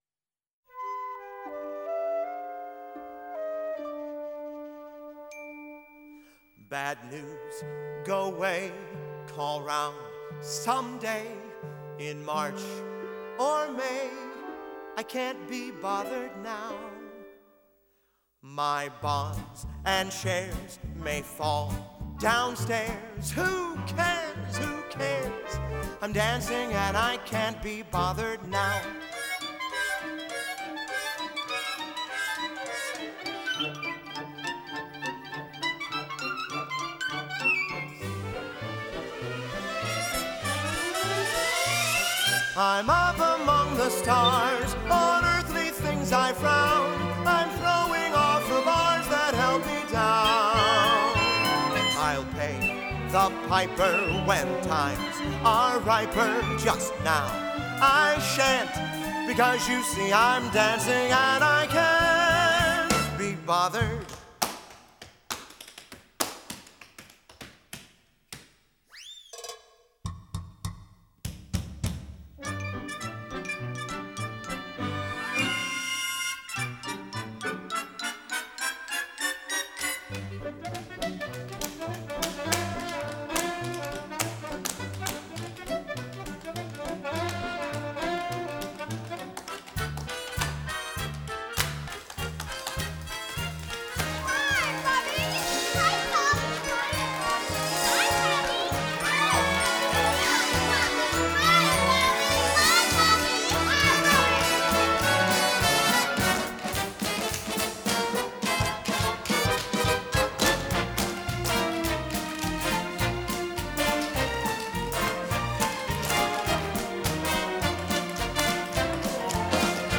Original London Cast Recording